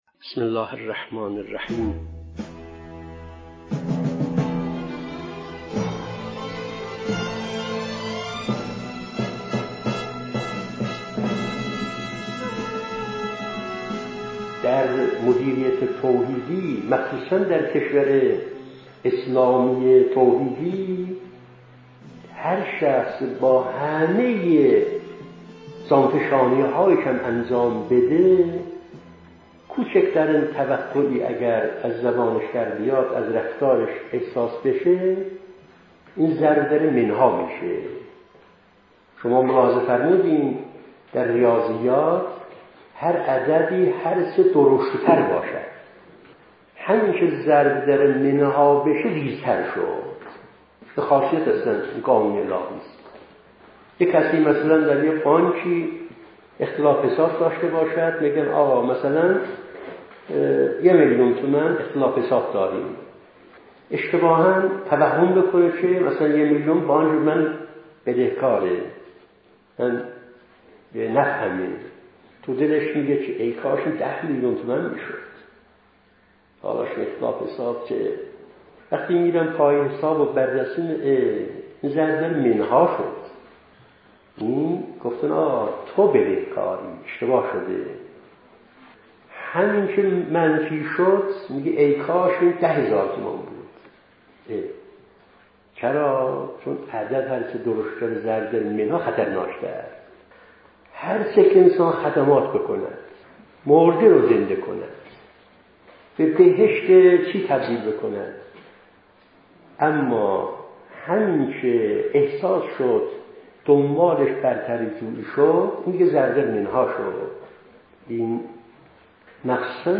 مدت سخنرانی : 7 دقیقه